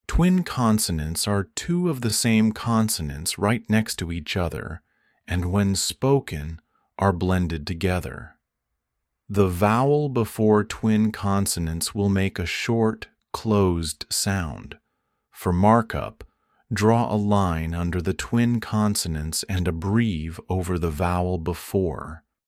twin-consonants-lesson.mp3